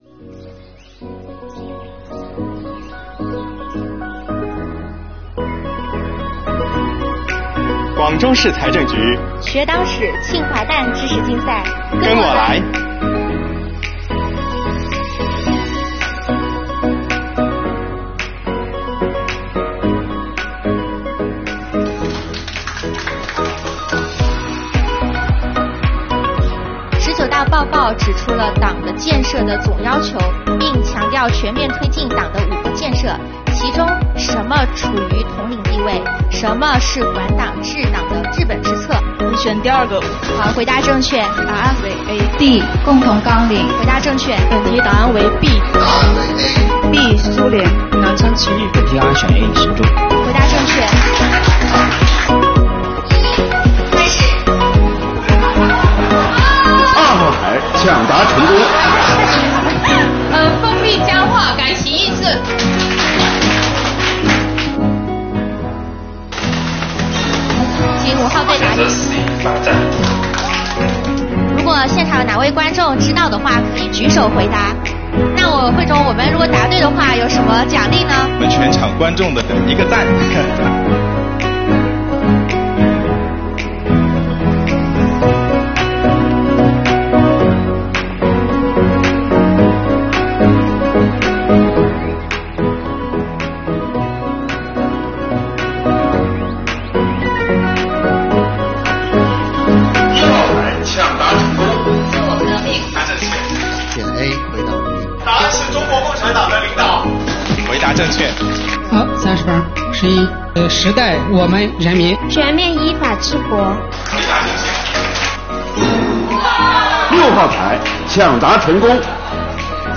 点击下方视频一起来看看比赛现场
比赛过程中穿插设置了党史知识问答、“听歌识曲”等观众互动环节，多名局领导带头参与，场下观众踊跃抢答，现场欢声笑语不断，气氛热烈而融洽。